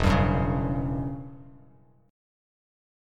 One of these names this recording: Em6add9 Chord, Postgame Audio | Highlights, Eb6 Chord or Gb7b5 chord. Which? Em6add9 Chord